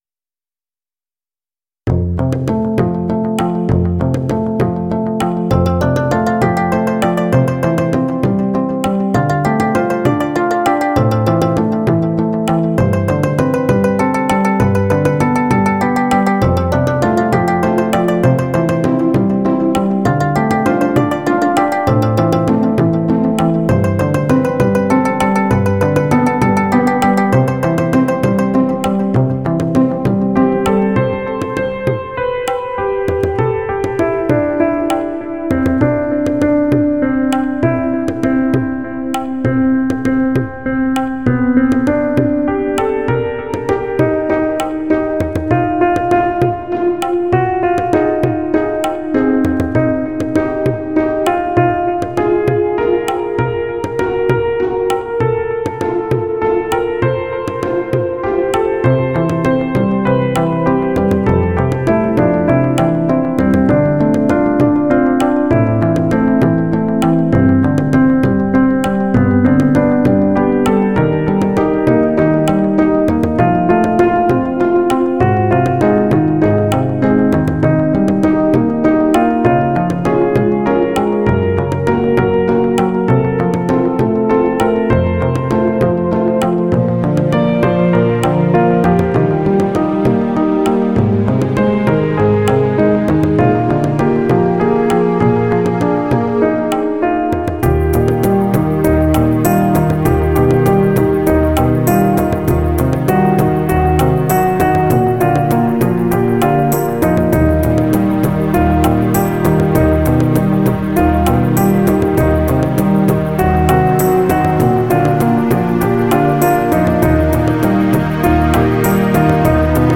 It goes thru too many chords, the melody hogs up all the space between phrases, and the meter shifts unnecessarily.
I made an instrumental version, which retains the clumsy composition's missteps, albeit the arrangement is new.